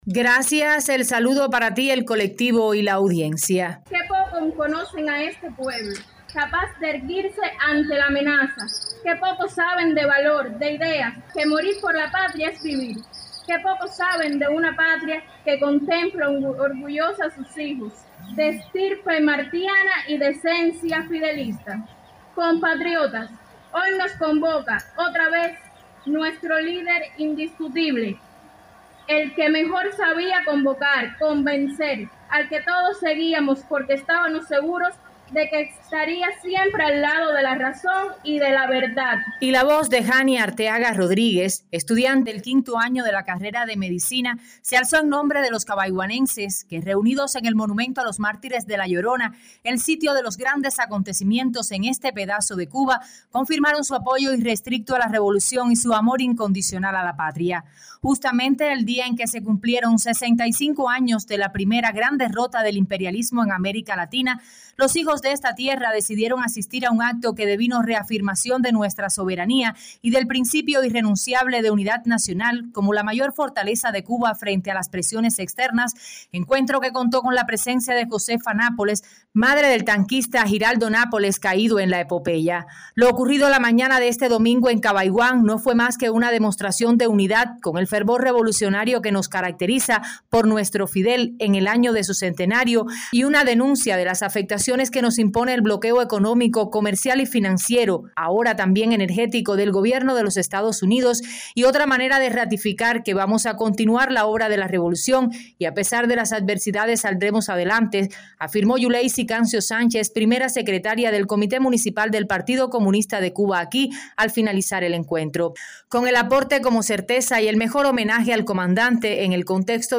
La Victoria de Playa Girón ocurrida el 19 de abril de 1961 se celebró en Cabaiguán con un acto de reafirmación revolucionaria que tuvo en los trabajadores de los distintos sindicatos a los protagonistas